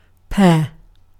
Ääntäminen
Tuntematon aksentti: IPA: /ˈbɪr.nə/